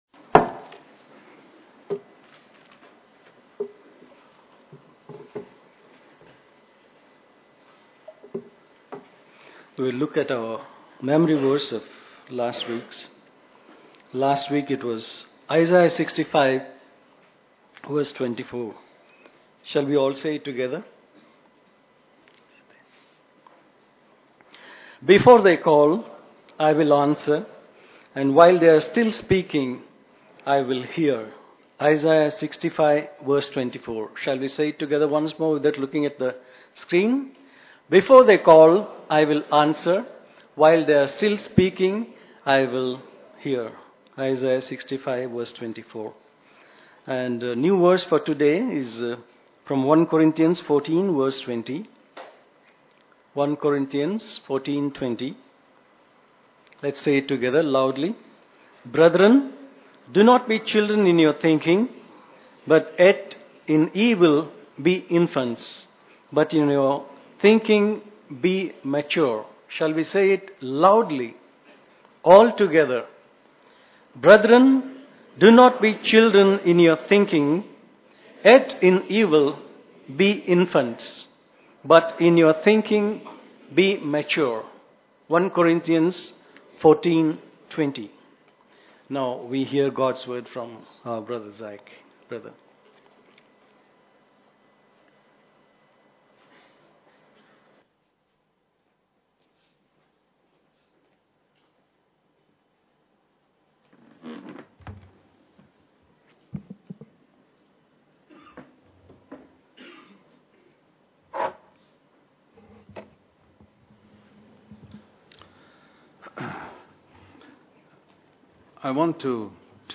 Righteousness with Peace and Joy Watch the live stream of the Sunday service from CFC Bangalore.